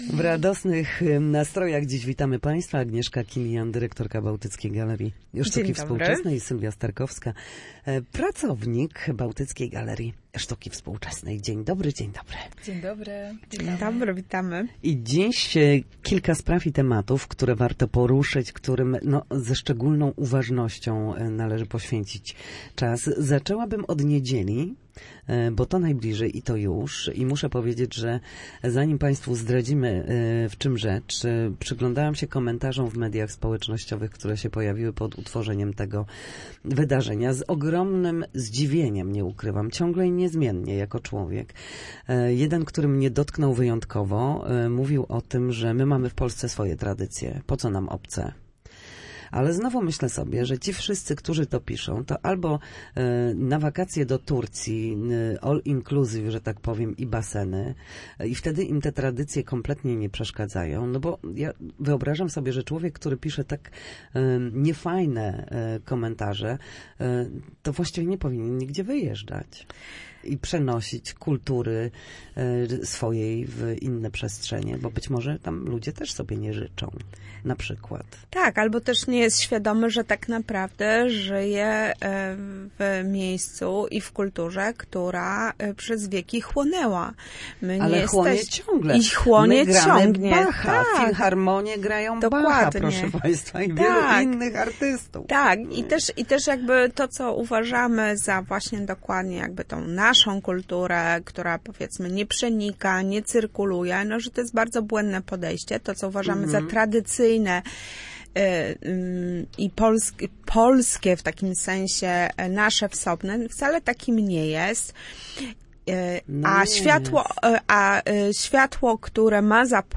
Na naszej antenie zapraszały na grudniowe wydarzenia.